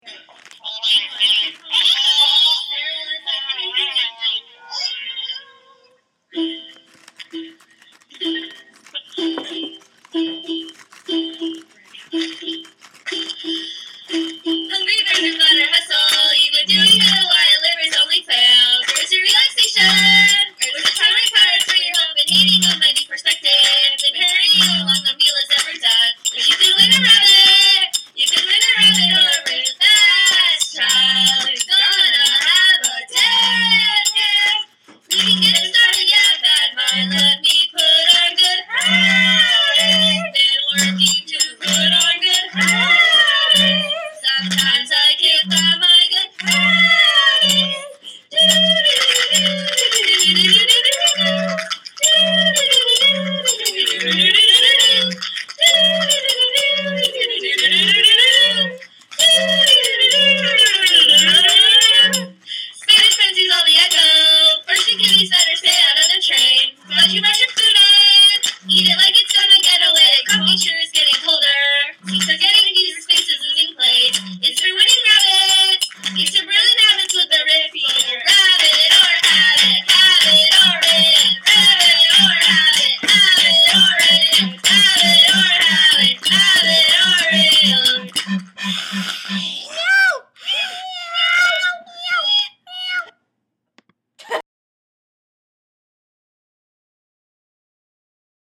a rollicking jaunt, one to be sung at the top of the lungs